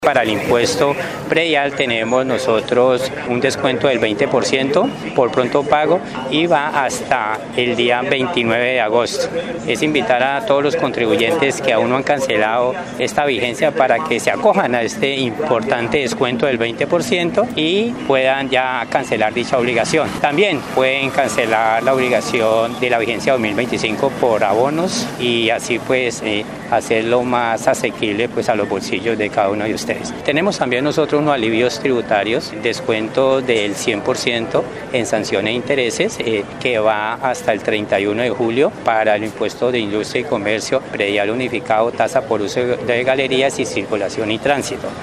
Nilson Moreno, secretario de hacienda municipal, explicó que, hasta el 31 de julio, habrá un descuento del 100% en sanciones e intereses, para impuestos como industria y comercio, predial unificado, uso de galería y circulación y tránsito, de vigencias anteriores.